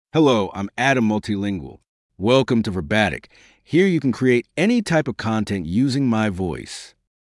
Adam MultilingualMale English AI voice
Adam Multilingual is a male AI voice for English (United States).
Voice sample
Listen to Adam Multilingual's male English voice.
Adam Multilingual delivers clear pronunciation with authentic United States English intonation, making your content sound professionally produced.